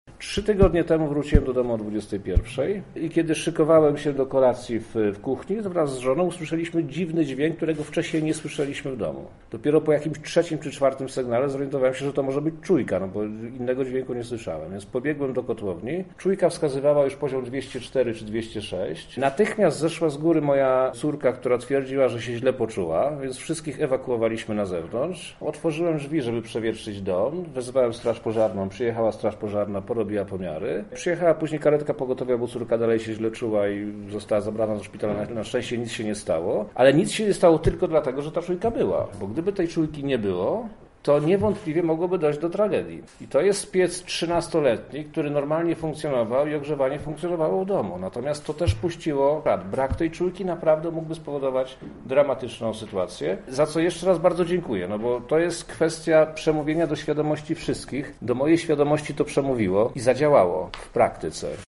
Niebezpieczeństwo związane z czadem odczuł na własnej skórze wojewoda lubelski Przemysław Czarnek, o czym opowiedział na konferencji prasowej: